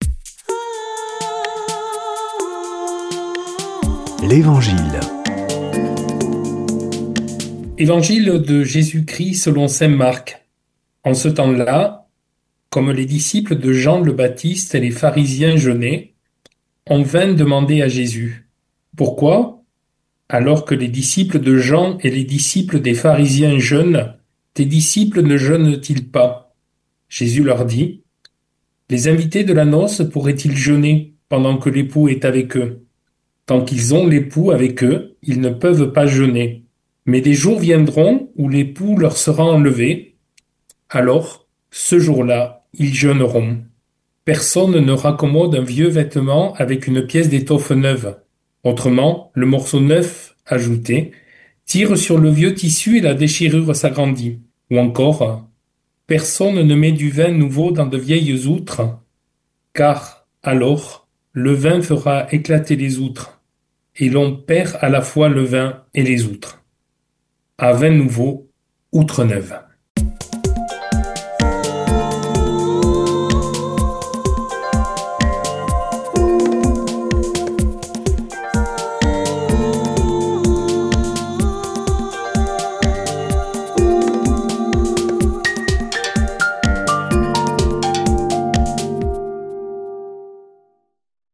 Des prêtres de la région